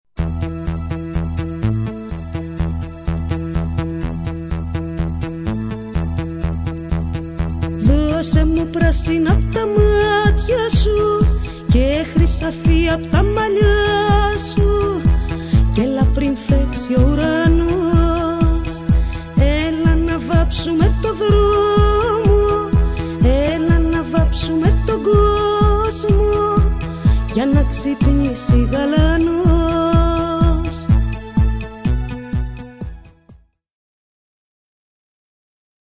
Παρατήρησε πώς έχει αλλάξει το demo που άκουσες πριν από λίγο μετά την τελική ηχογράφηση και την επεξεργασία του στο studio.